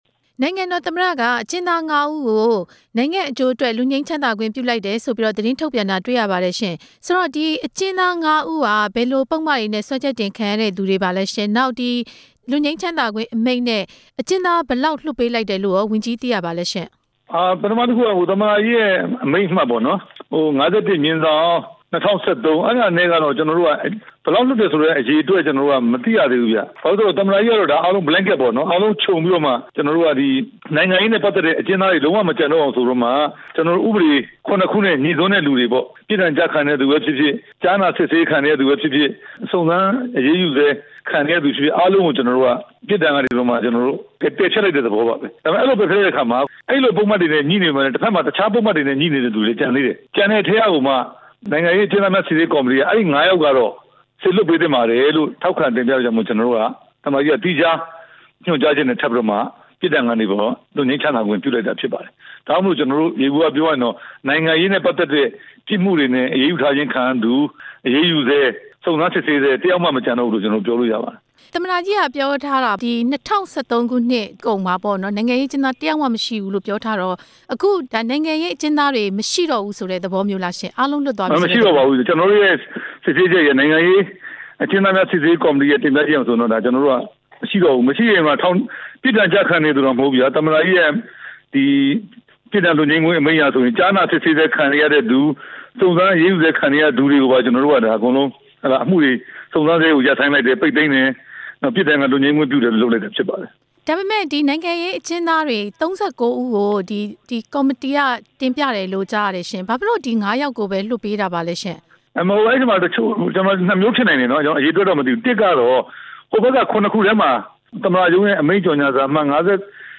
ပြန်ကြားရေးဒုတိယဝန်ကြီး ဦးရဲထွဋ်ကို ဆက်သွယ်မေးမြန်းချက်